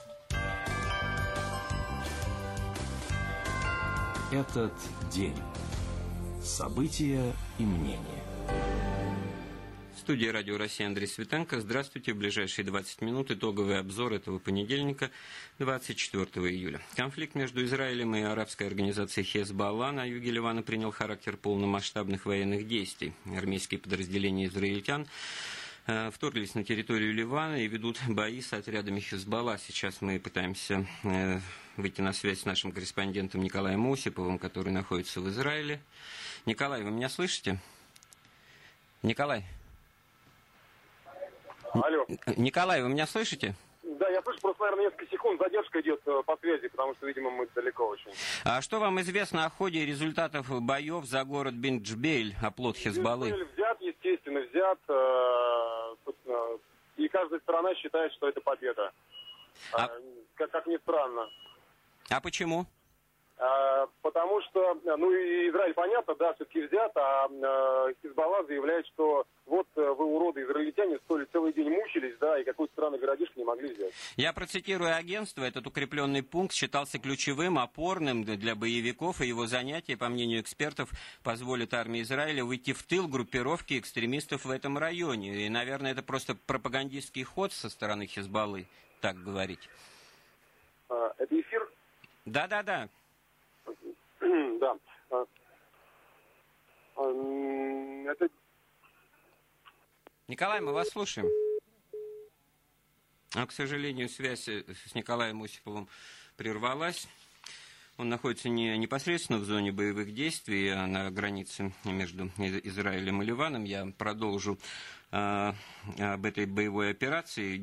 "Радио России". Прямой эфир. 24 июля 2006 года. Передача "Этот день: события и мнения".